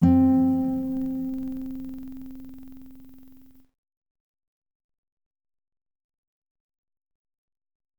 I’m getting a lot of distortion when converting to WAV ADPCM.